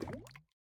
drip_lava6.ogg